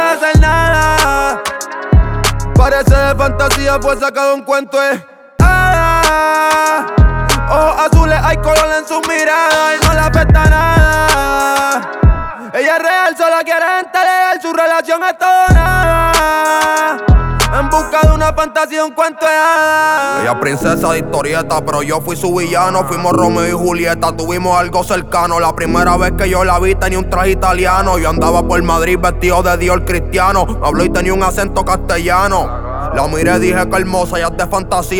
Жанр: Латиноамериканская музыка / Рэп и хип-хоп
# Latin Rap